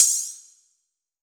VEE Open Hihat 016.wav